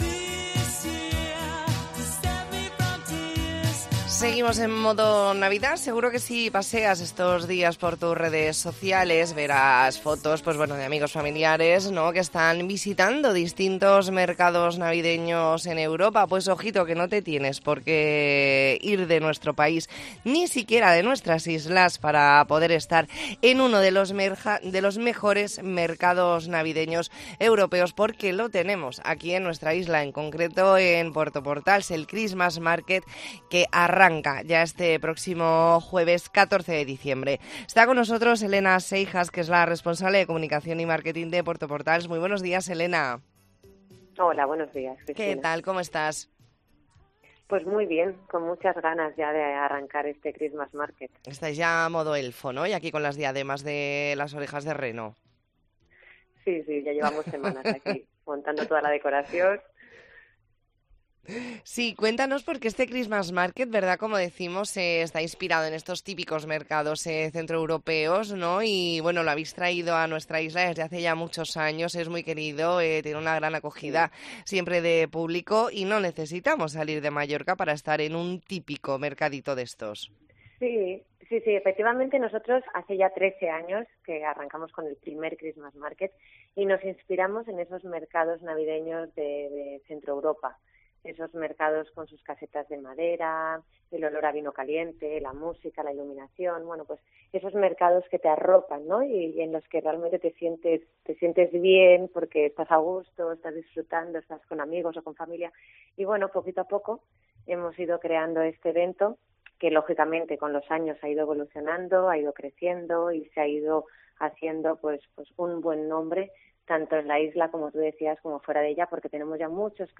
ntrevista en La Mañana en COPE Más Mallorca, martes 12 de diciembre de 2023.